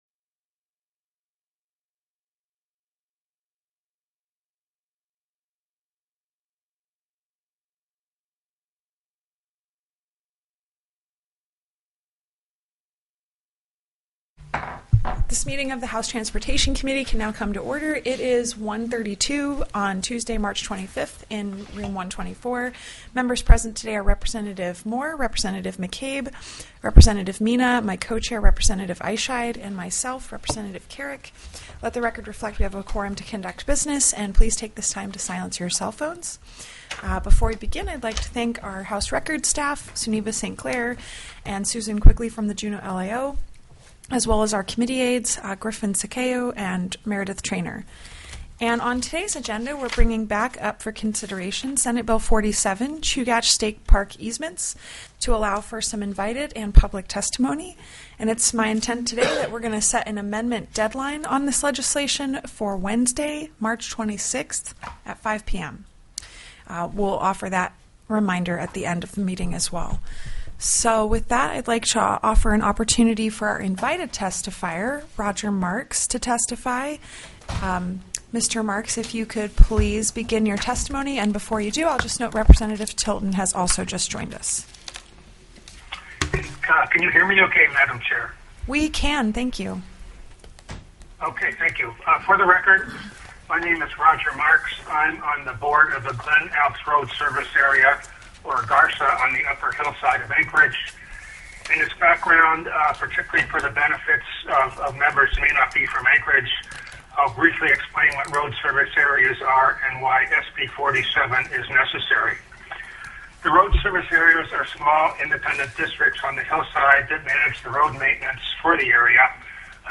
The audio recordings are captured by our records offices as the official record of the meeting and will have more accurate timestamps.
+= SB 47 CHUGACH STATE PARK EASEMENTS TELECONFERENCED
-- Invited & Public Testimony --